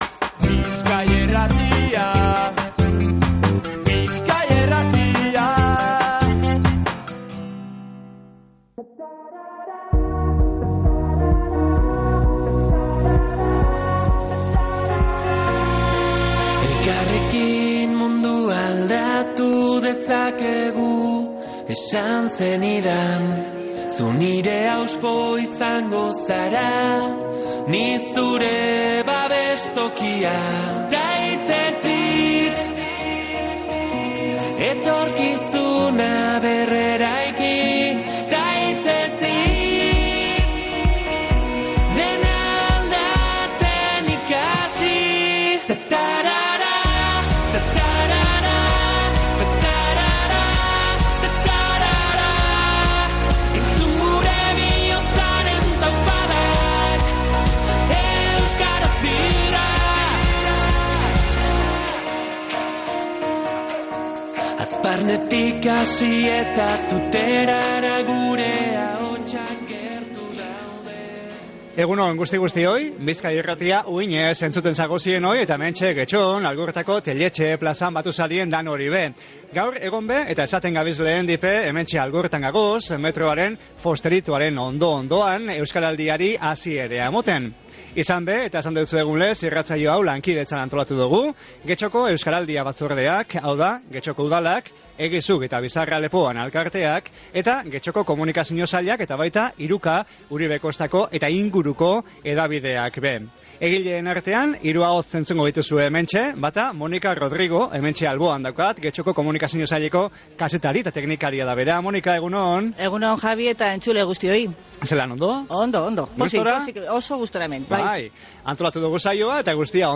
EUSKARALDIA-SAIOA-GETXOTIK.mp3